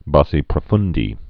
(bäsē prə-fndē)